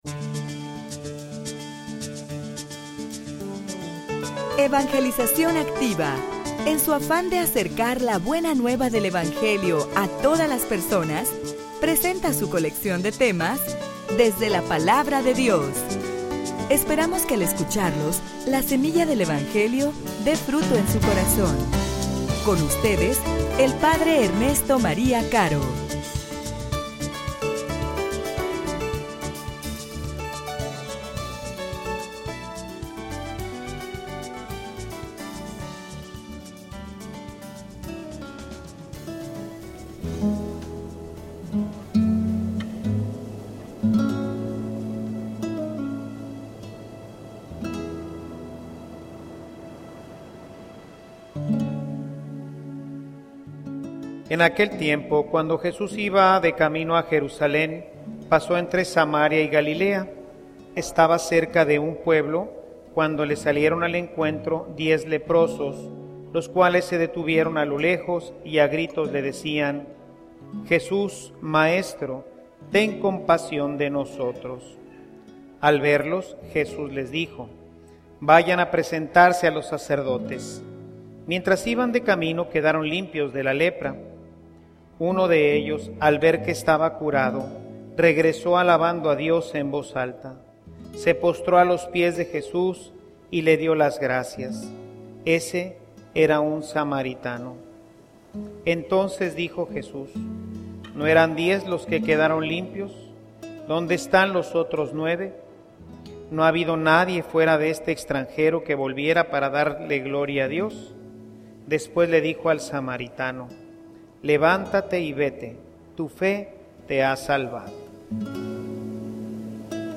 homilia_Por_que_complicarse_la_vida.mp3